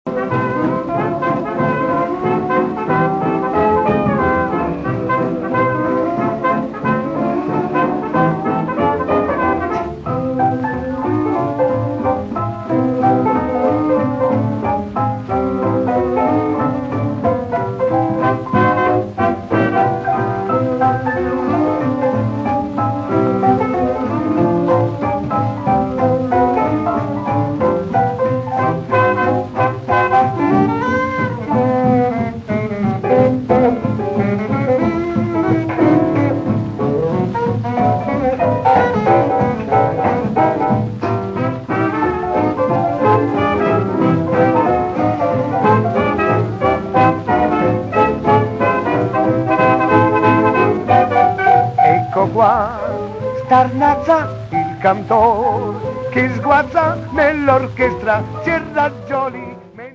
voce
riversati su cd da 78 giri degli anni 40